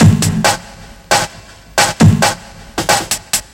Dirty Break 135.wav